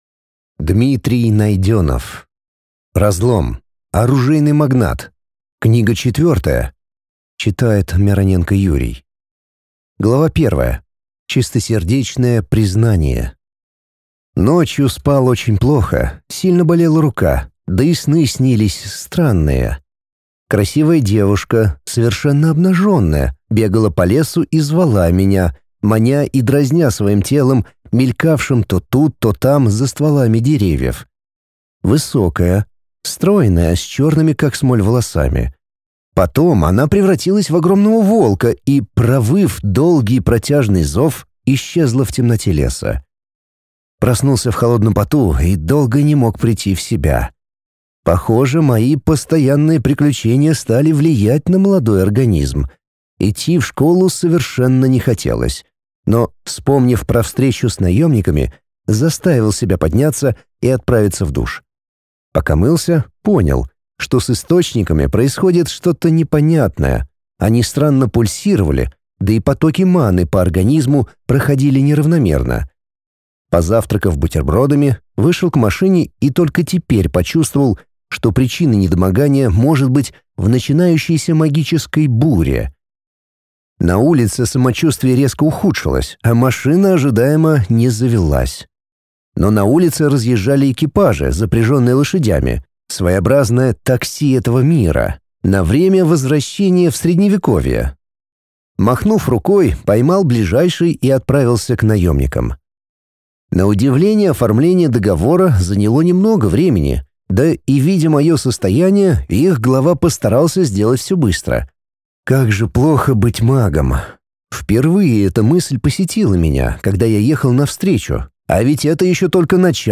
Аудиокнига Разлом. Оружейный магнат. Книга четвёртая | Библиотека аудиокниг